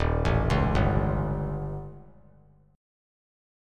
D#7b5 Chord
Listen to D#7b5 strummed